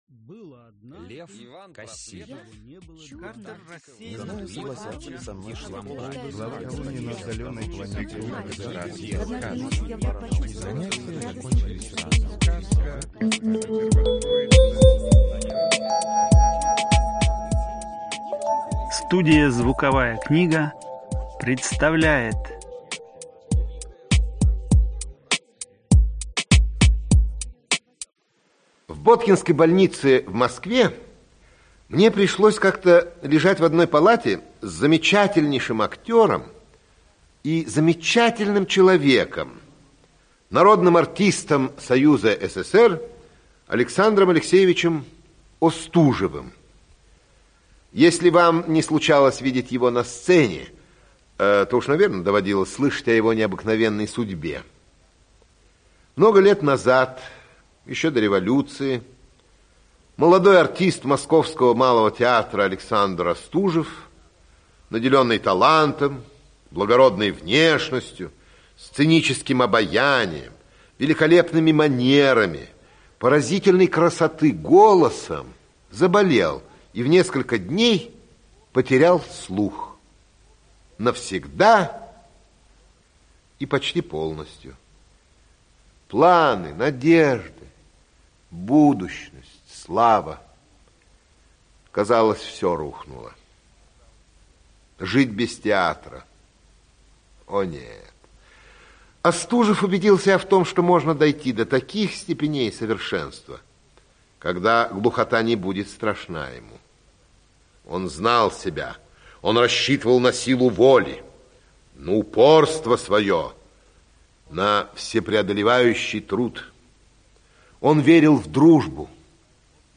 Аудиокнига Из жизни Остужева | Библиотека аудиокниг
Aудиокнига Из жизни Остужева Автор Ираклий Андроников Читает аудиокнигу Ираклий Андроников.